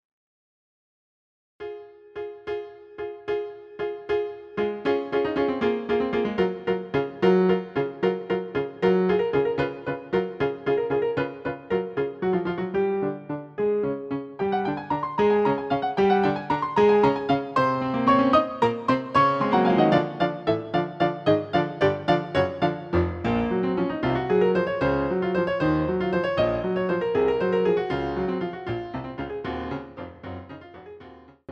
CD quality digital audio Mp3 file
using the stereo sa1mpled sound of a Yamaha Grand Piano.